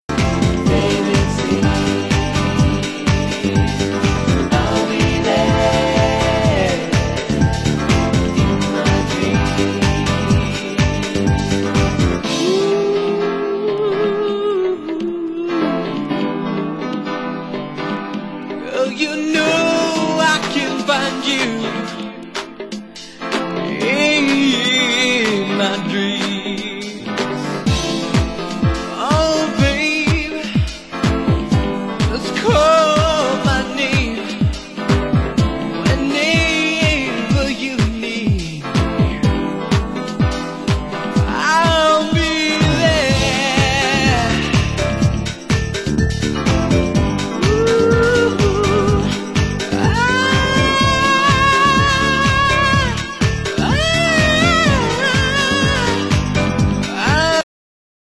盤質：チリパチノイズ有　　ジャケ：軽度のリングウェア有